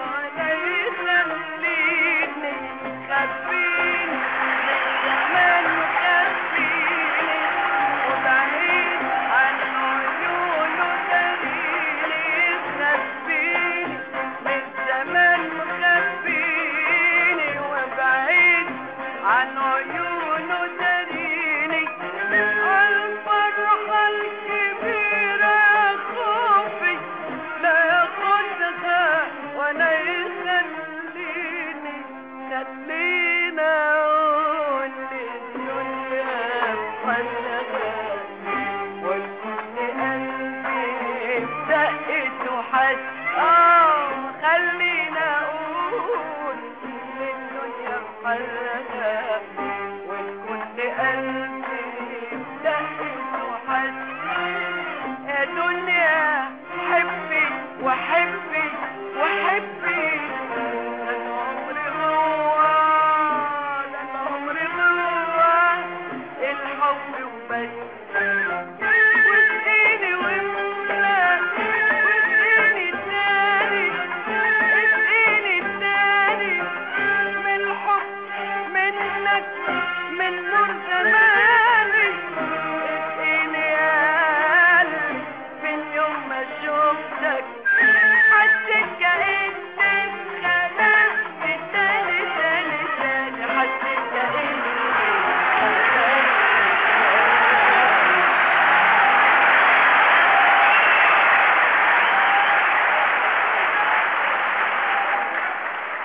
Dialect: Egyptian Colloquial Maqam: rast